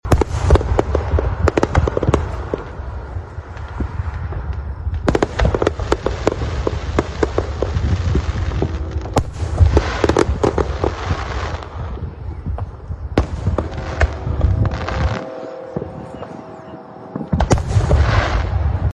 Emporians enjoy annual July 4 fireworks show
The bombs were definitely bursting in air at Emporia State University for the city’s annual fireworks display.
2534-fireworks-2.mp3